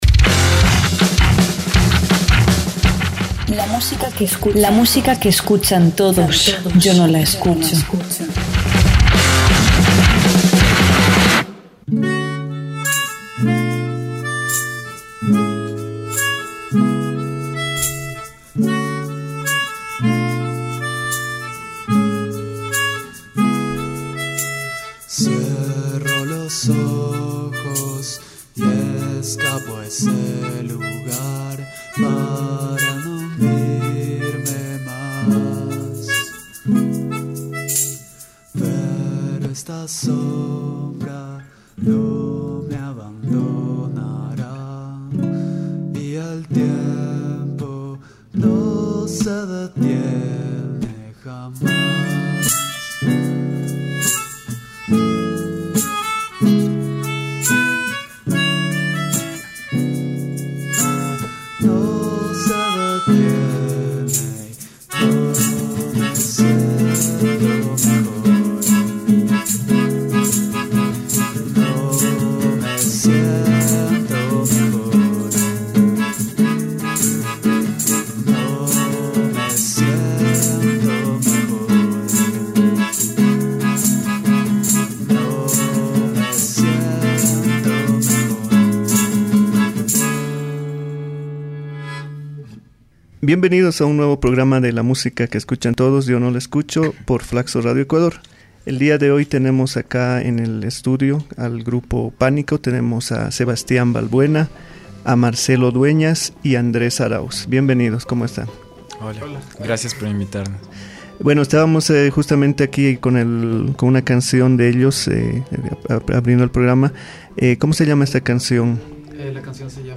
El grupo nos visitó en los estudios de Flacso Radio donde conversamos sobre su música, sus discos y los proyectos futuros de esta banda ecuatoriana. Además, Pánico interpretó un set acústico con algunas de sus mejores canciones.